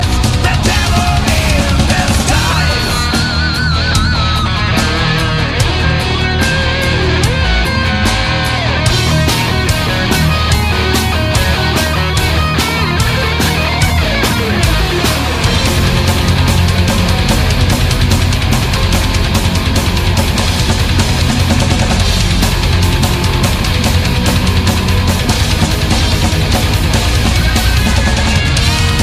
Heavy metal guitar solo ringtone free download